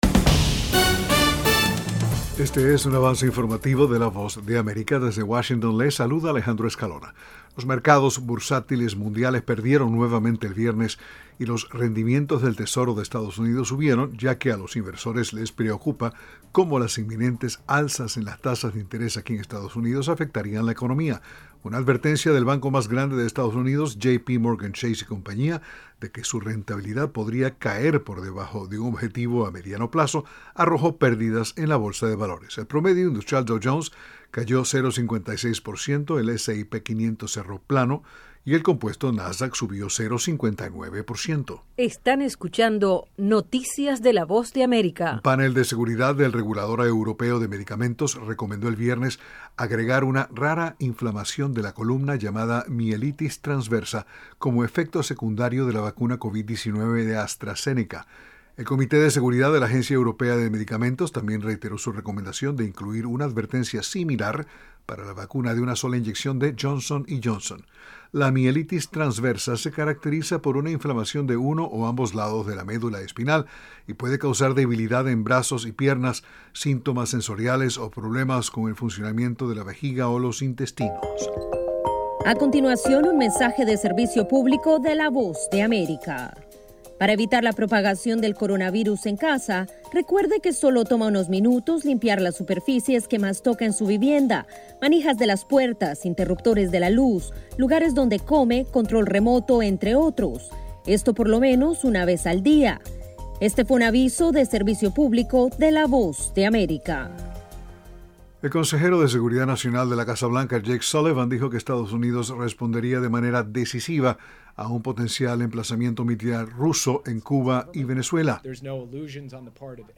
Avance Informativo 6:00pm